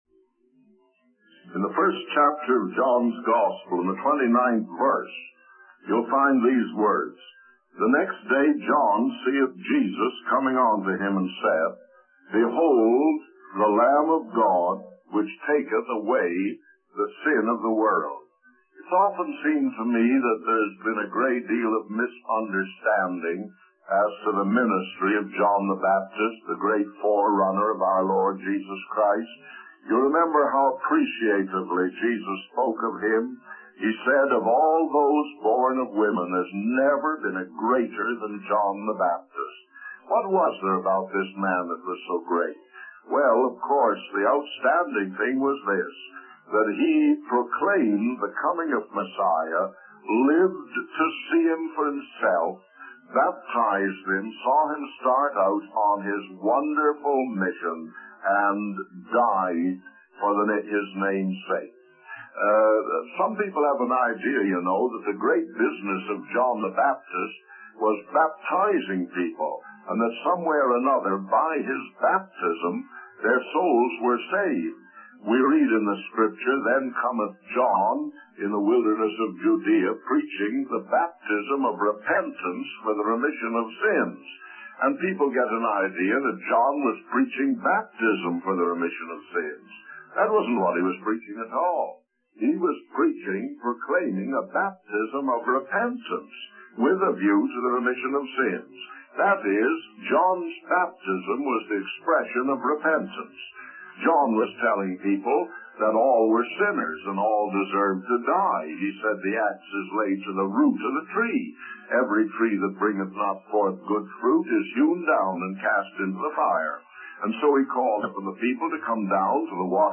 The sermon emphasizes the importance of understanding the true purpose of John the Baptist's ministry and the significance of the Lamb of God in taking away the sin of the world.